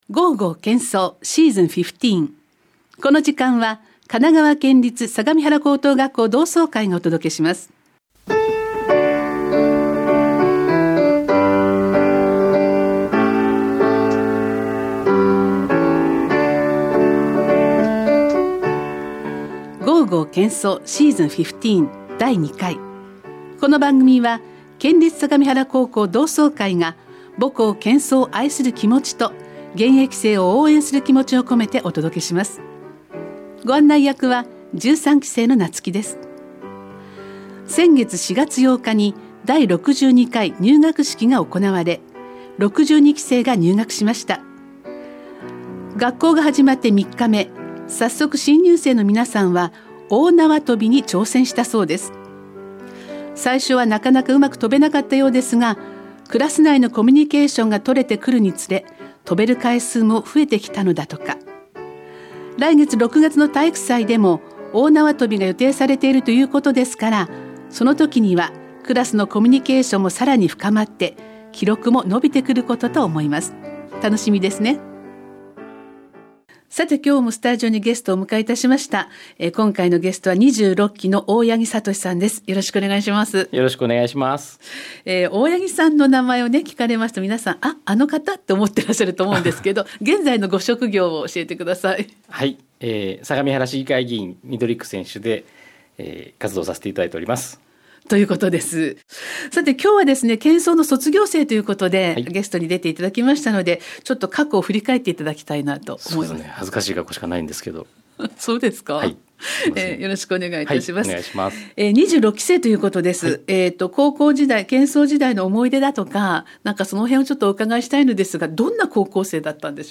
２０２５年５月８日放送 シーズン１５ 第２回目の出演者は、２６期生 相模原市議会議員の大八木 聡さんです。